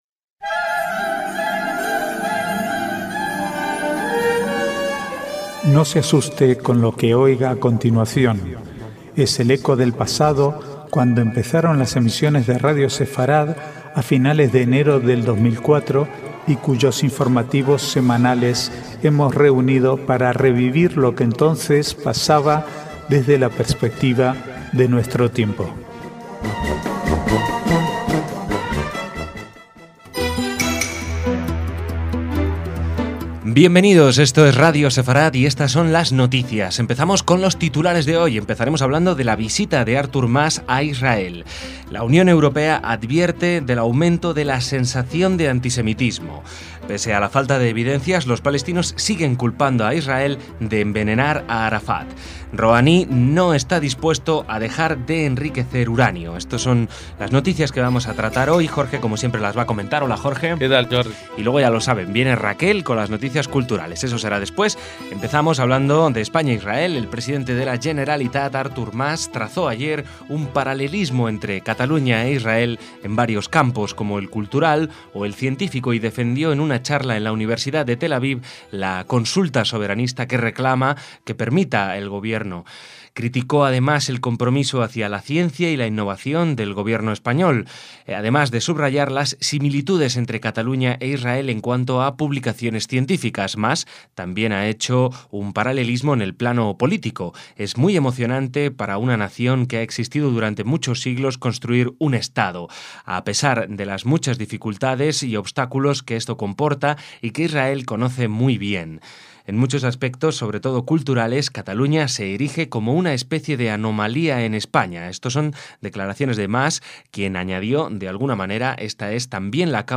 Archivo de noticias del 12 al 15/11/2013